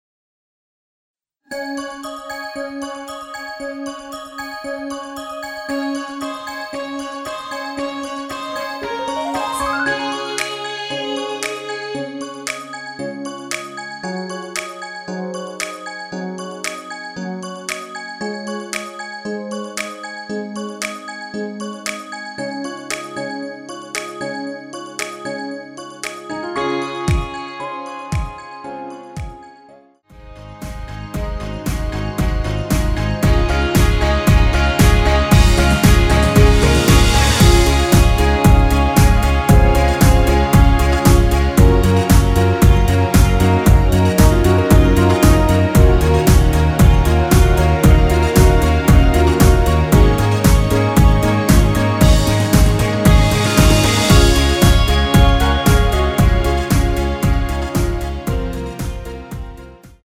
엔딩이 페이드 아웃이라 노래 부르기 좋게 엔딩 만들었습니다.
Db
◈ 곡명 옆 (-1)은 반음 내림, (+1)은 반음 올림 입니다.
앞부분30초, 뒷부분30초씩 편집해서 올려 드리고 있습니다.